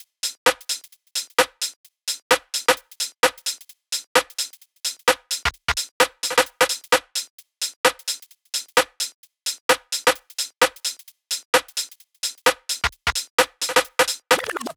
UK Garage